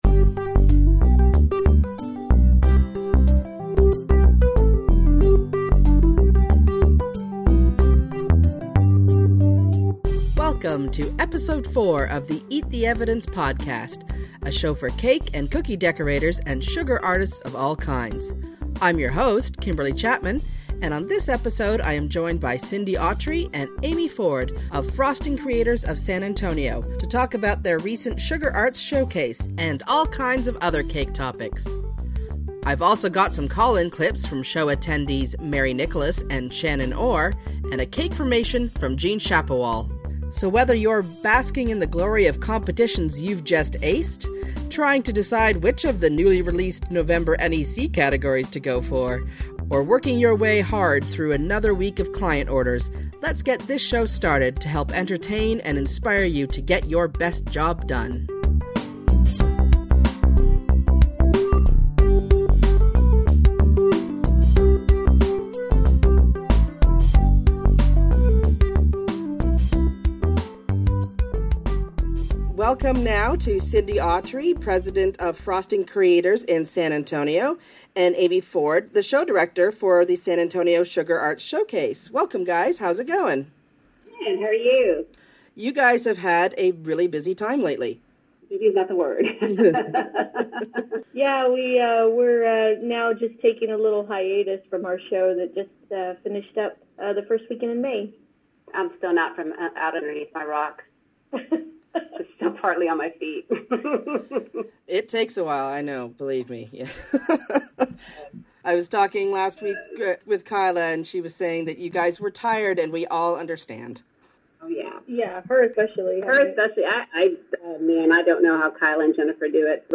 Music/Sound Credits The following songs and sound clips were used in this episode of Eat the Evidence.